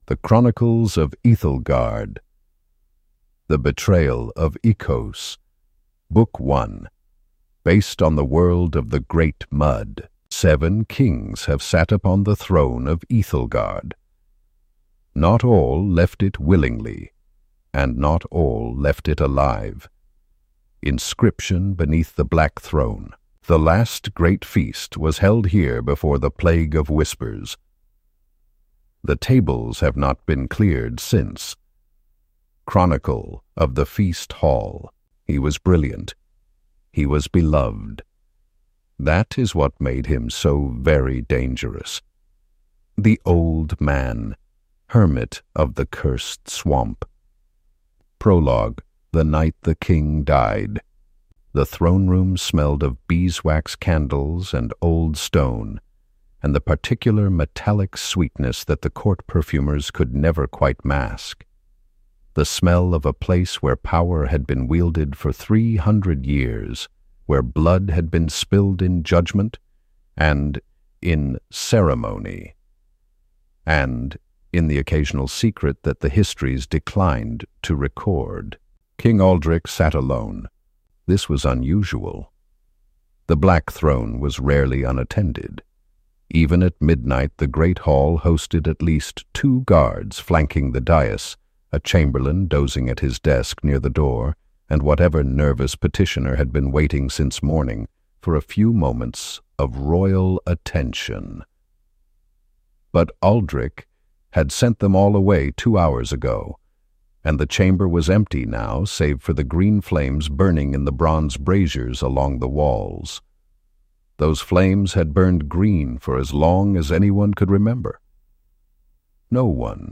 Full narration with 16 unique character voices.